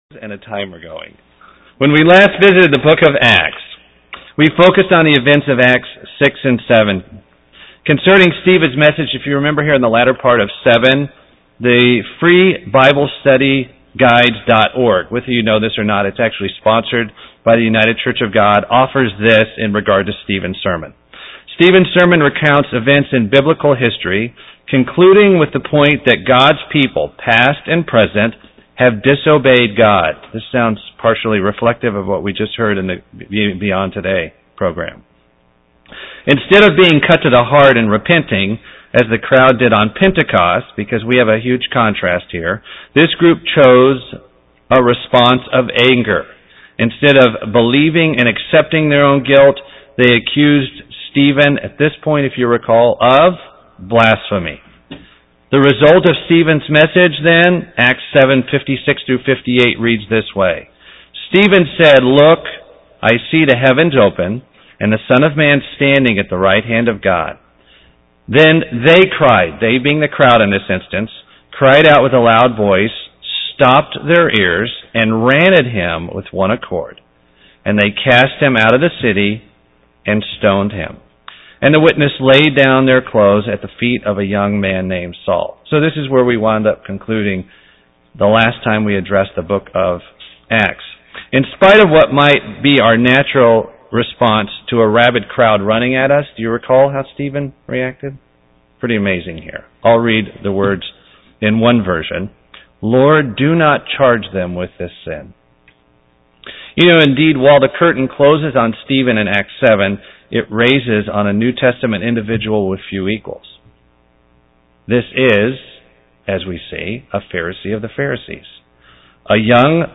Chapters 8 and 9 are discussed in this ongoing Bible study on the book of Acts.
UCG Sermon Studying the bible?